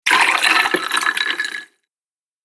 دانلود آهنگ آب 66 از افکت صوتی طبیعت و محیط
دانلود صدای آب 66 از ساعد نیوز با لینک مستقیم و کیفیت بالا
جلوه های صوتی